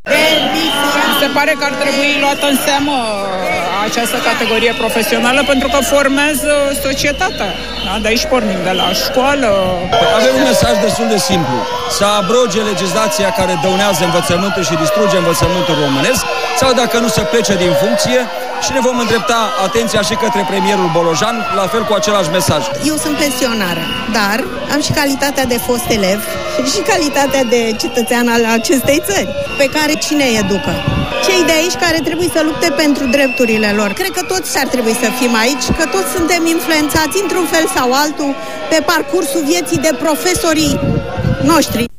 Peste 400 de profesori s-au adunat din nou în fața sediului Ministerului Educației, miercuri, 6 august, în a șasea zi de proteste față de măsurile luate de guvern pentru reducerea deficitului bugetar.